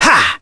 Fluss-Vox_Attack1.wav